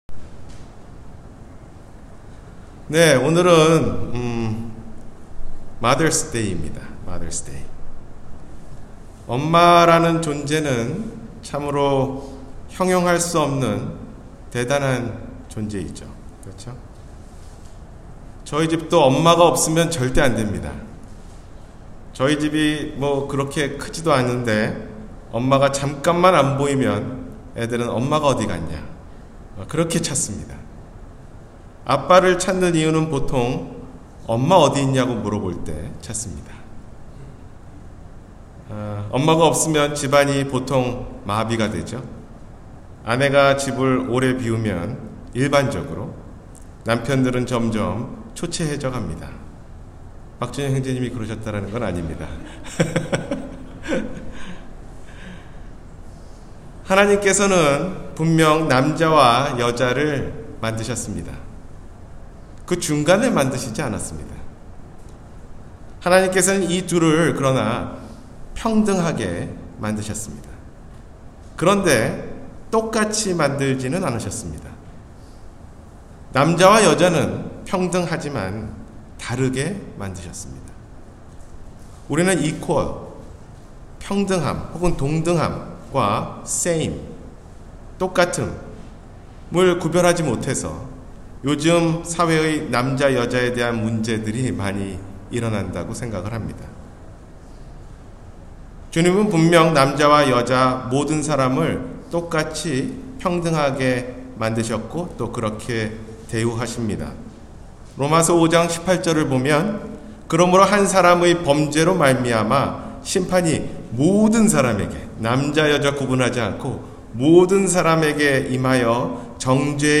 현숙한 여인의 조건 – 주일설교